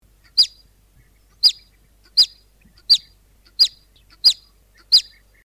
Index of /userimages/file/Voice/animals